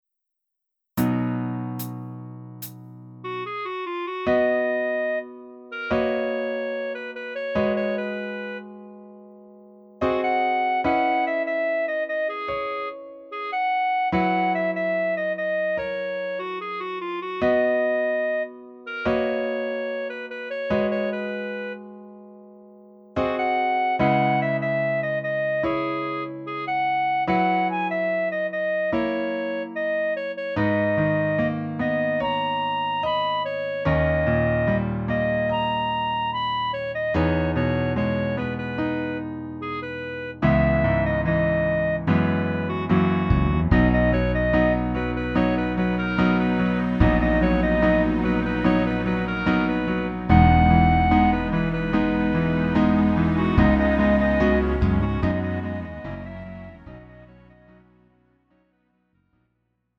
음정 -1키 4:28
장르 가요 구분 Lite MR